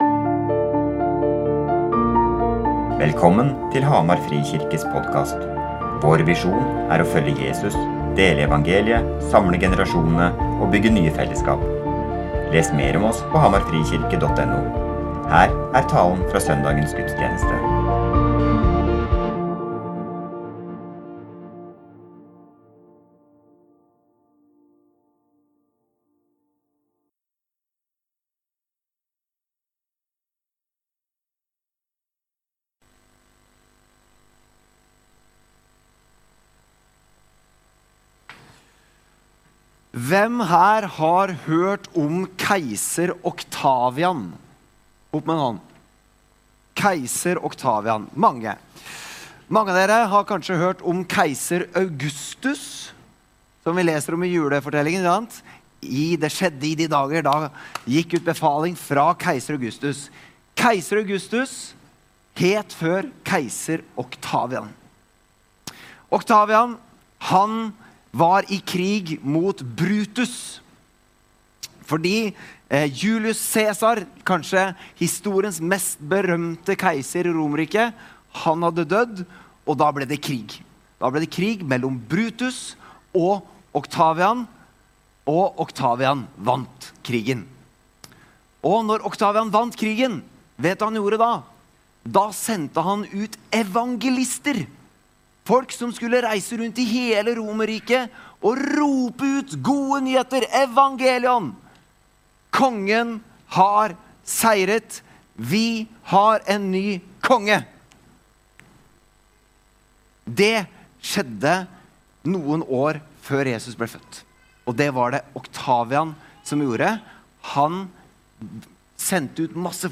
Johannesevangeliet bruker ordet «Konge» hele 10 ganger i sin påskefortelling, for å få frem budskapet om at Jesus er Konge. I denne preken fra 1. påskedag